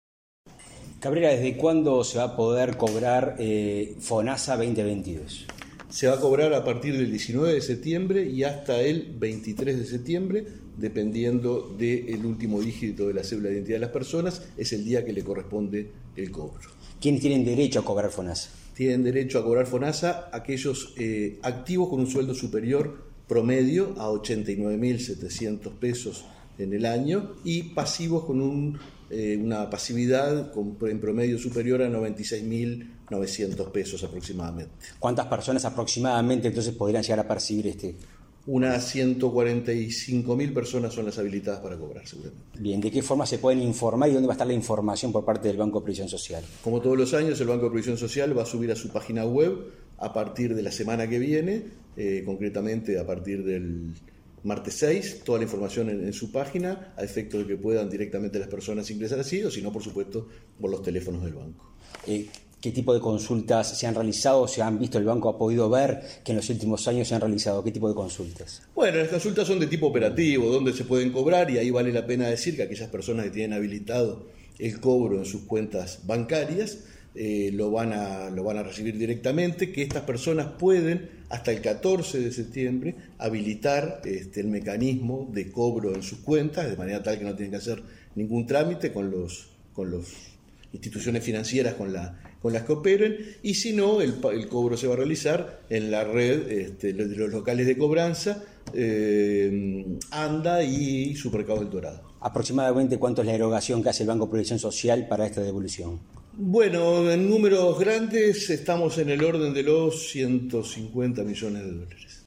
Entrevista al presidente del BPS, Alfredo Cabrera